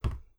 Impacts
fruit2.wav